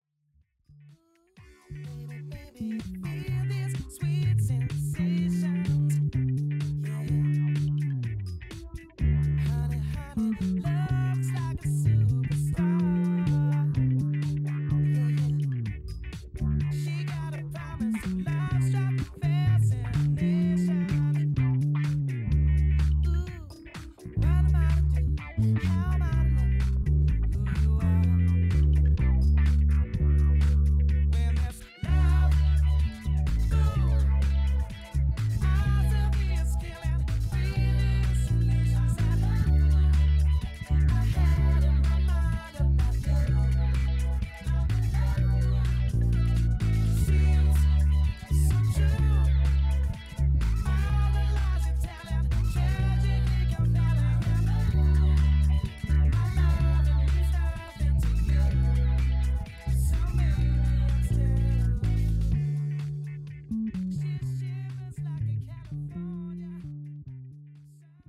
So, bisschen Latenzprobleme (ewig nichts aufgenommen und muss da mal wieder alles richtig konfigurieren), aber hab doch mal kurz was gestrickt und einfach mal einen Übetake mitgeschnitten. Ohne Plek allerdings.
Bass ist übrigens der Harley Benton PB-50 mit La Bella Low Tension Flats, Höhenblende fast komplett zu über Kompressor und VT Bass gejagt, kein weiteres Editing. your_browser_is_not_able_to_play_this_audio